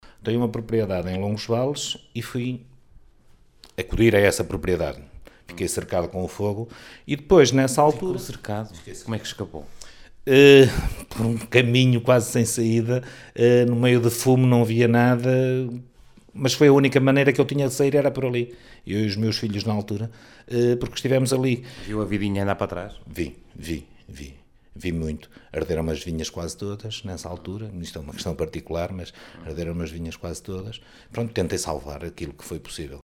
“Fiquei praticamente cercado pelas chamas. Eu e os meus filhos”, contou aos microfones da Rádio Vale do Minho.